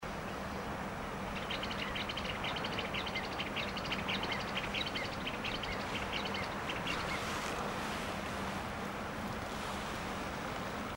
Greater Wagtail-Tyrant (Stigmatura budytoides)
Life Stage: Adult
Detailed location: Laguna Guatraché
Condition: Wild
Certainty: Recorded vocal